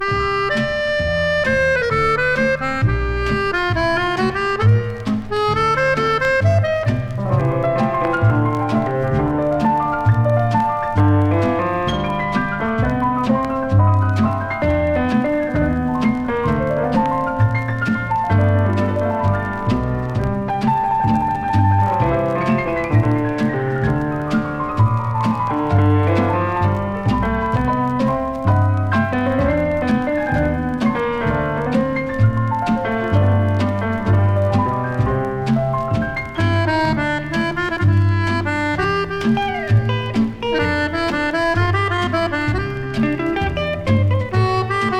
Jazz, Pop, Easy Listening　USA　12inchレコード　33rpm　Stereo